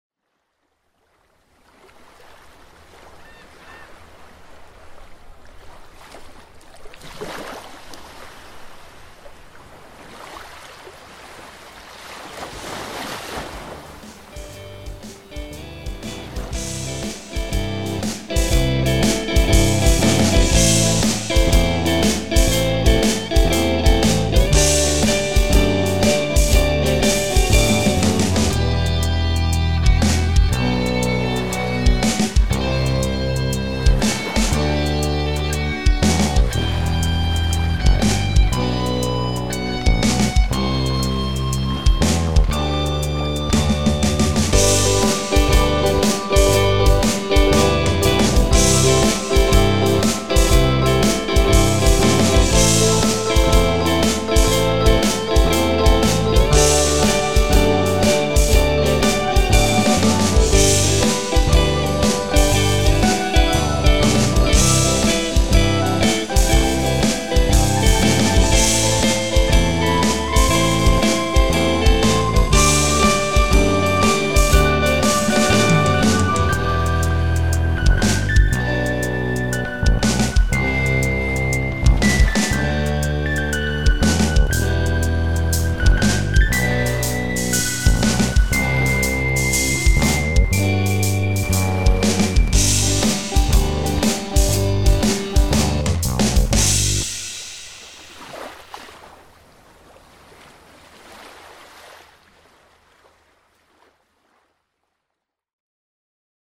Mixing / Mastering Feedback on Surfrock Instrumental
It should be relaxed, not overly perfected, a bit like a jamming session at a beach. Therefore the ambience noise ;)
Its not the tightest performance which fits for surfrock ;)
Drums: MT Drums with brickwall limiter, Synths: HALion with midi keyboard, Bass: fretless with DI and Compr, Guitars: DI with VST Amp Rack presets
I boosted (Maximizer, DaTube) it a little bit so that it peaks around -0,1
No EQ on the master.
When I listened it with another reference song it sounded, that the sound it was not quite in the face as I wanted it to be.
I think the mix is pretty good, although there are spots where the hi-hats seem to stick out too much. The drums are pretty clearly artificial in general, not sure if there's something you could do to make them sound a bit more natural. But the guitars and synths are done very nicely.